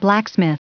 Prononciation du mot blacksmith en anglais (fichier audio)